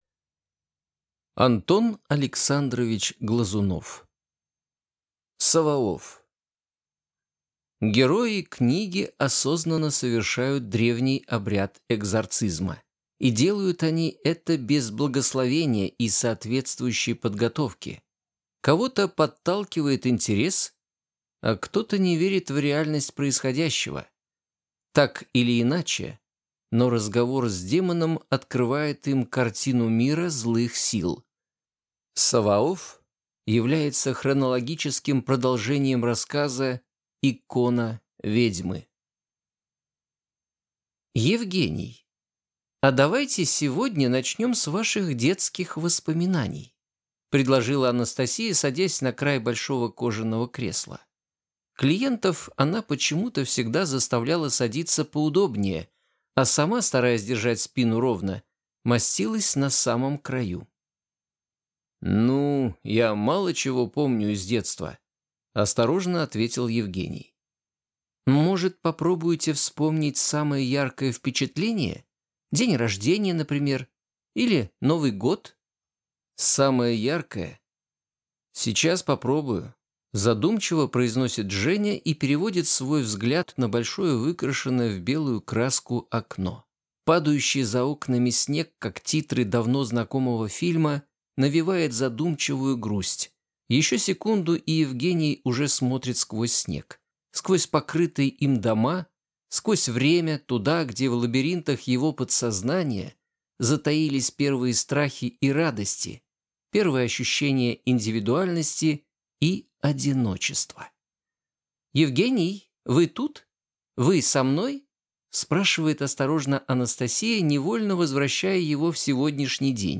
Аудиокнига Savaoff | Библиотека аудиокниг